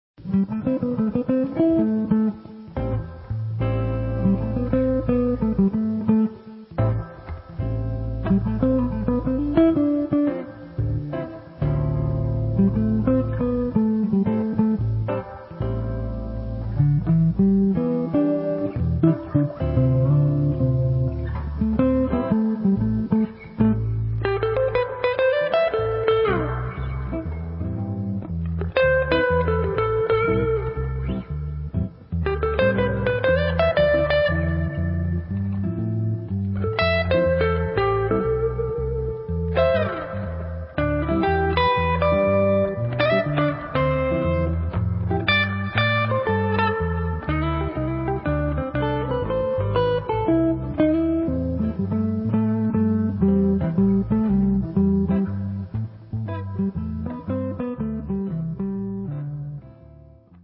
Two instrumental suites.